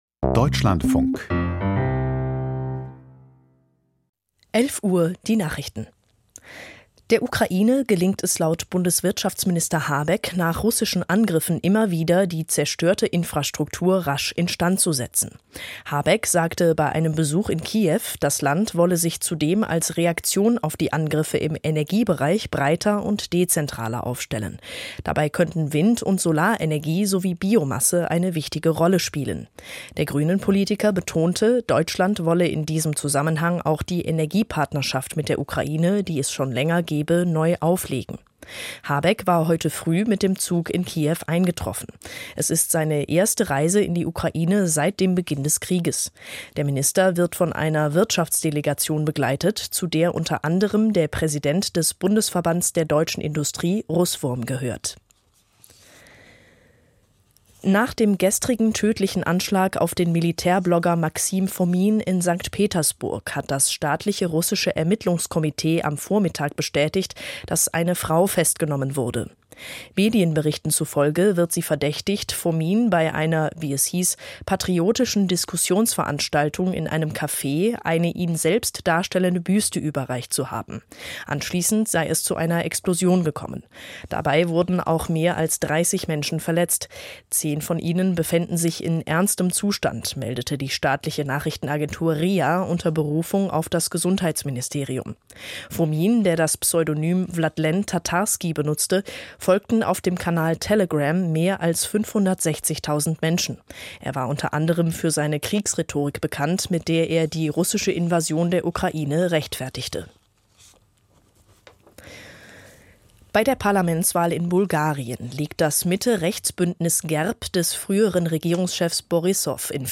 Nachrichten vom 03.04.2023, 11:00 Uhr